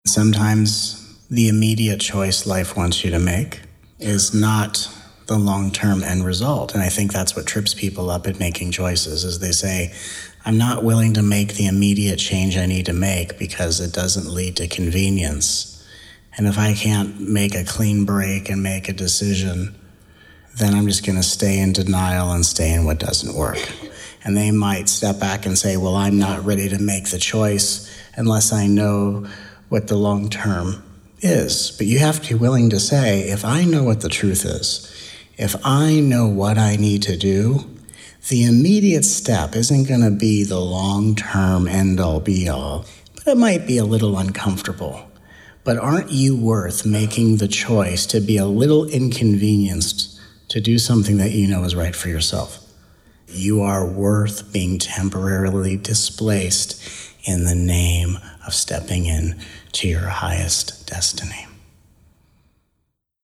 Please join us for these 14 hours of nourishing, uplifting, often hilarious, peaceful, and powerful teachings from this miraculous 5-day retreat.